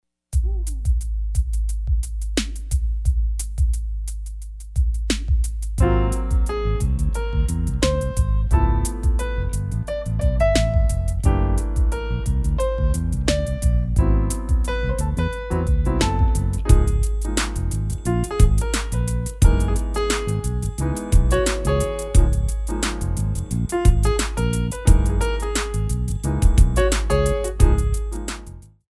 8 bar intro
moderato
Lyrical Jazz / Funk